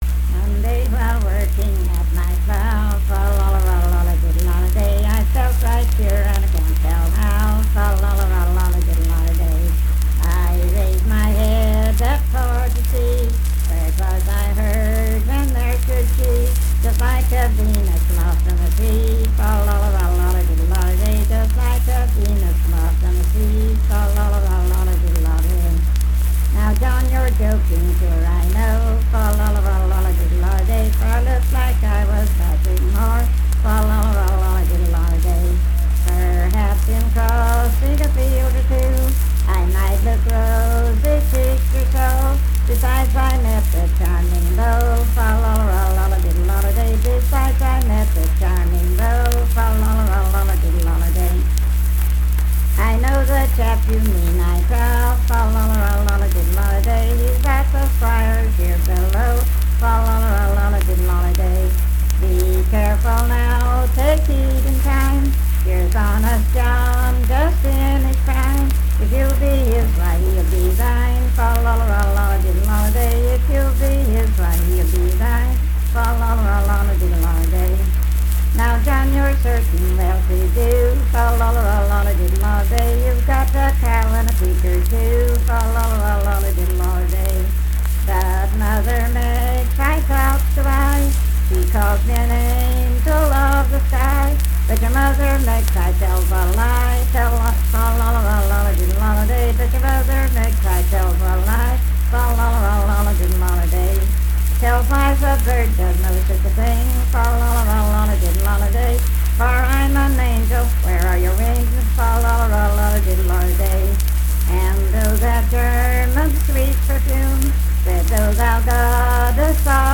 Unaccompanied vocal music performance
Verse-refrain 6 (10w/R).
Voice (sung)